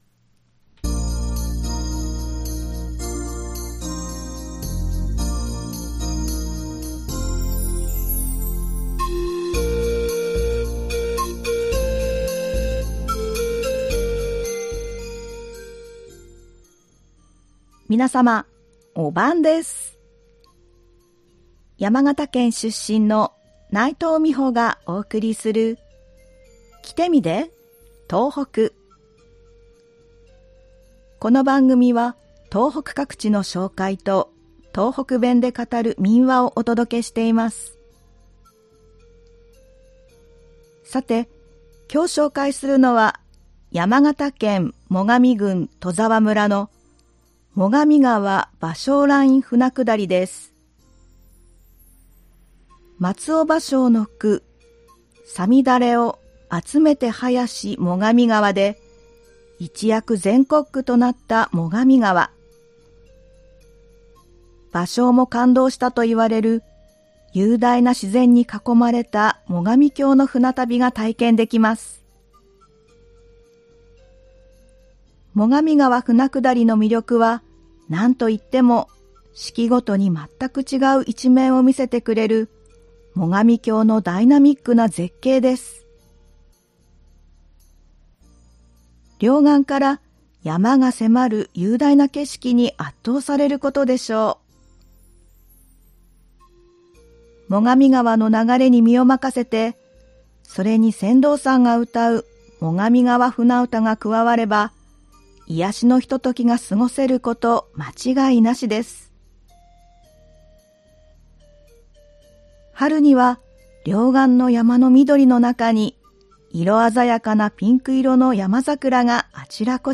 この番組は東北各地の紹介と、東北弁で語る民話をお届けしています。
ではここから、東北弁で語る民話をお送りします。今回は山形県で語られていた民話「犬のあし」です。